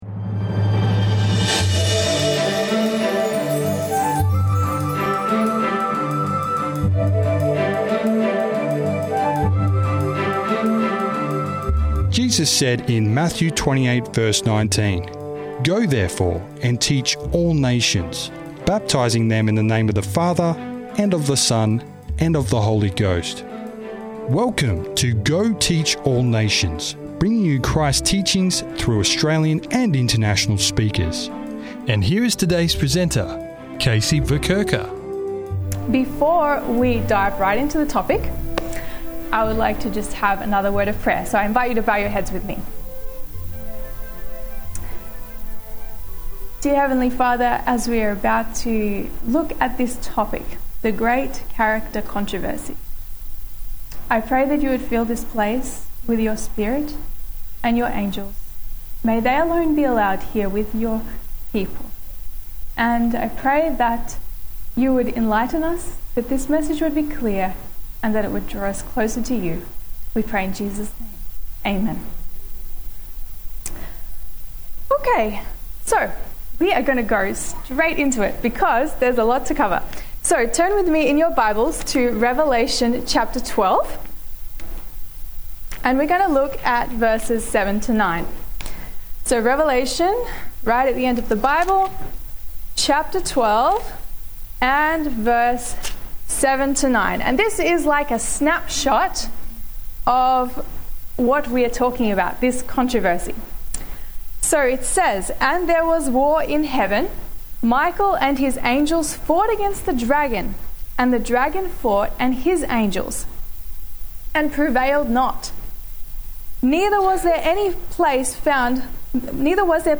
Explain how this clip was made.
This message was made available by the Waitara Seventh-day Adventist church.